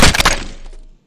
weap_med_gndrop_1.wav